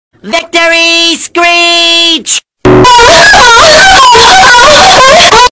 Play, download and share VictoryMoan original sound button!!!!
victorymoan.mp3